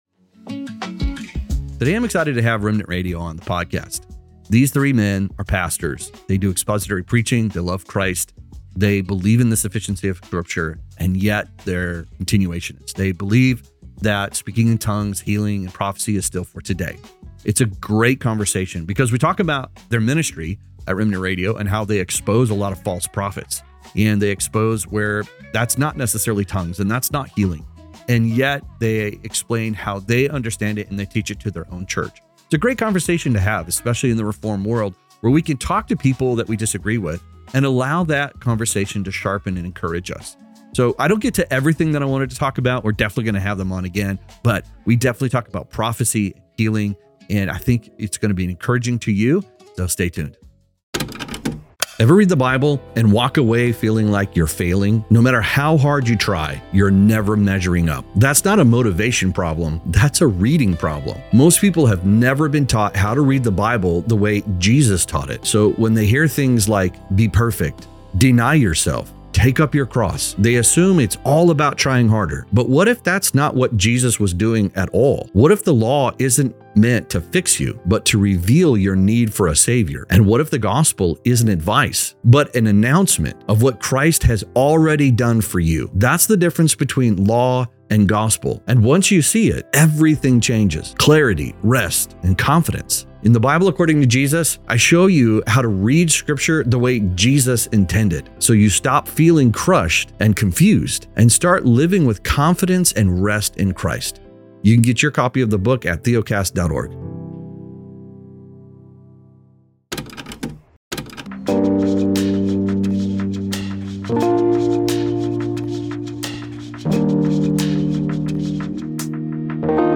These pastors share a commitment to Christ and the sufficiency of Scripture while holding to a continuationist view of spiritual gifts. You will hear a thoughtful discussion on prophecy, healing, and tongues, along with how they approach exposing false teachings.